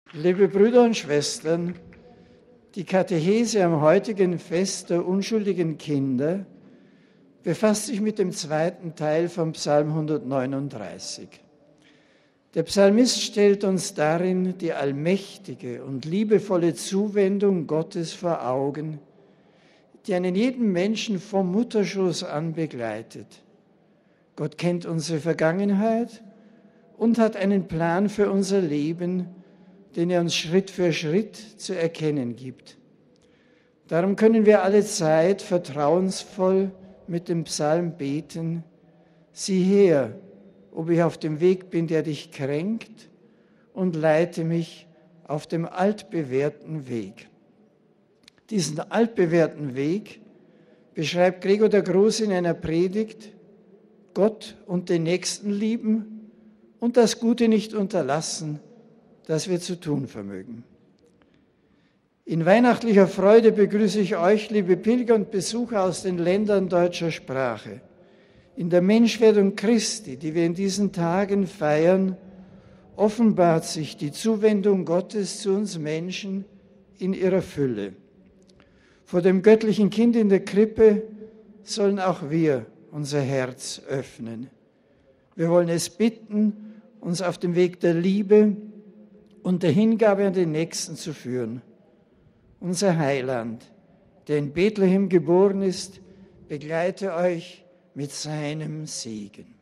Papst Benedikt hat heute Vormittag auf dem Petersplatz die 32. Generalaudienz seines Pontifikats gehalten. Trotz des relativ kalten Wetters traf der Papst die rund 20 000 Pilger im Freien. Dabei betonte er, dass auch die, die schwach im Glauben oder in der christlichen Praxis sind, zur Kirche gehören.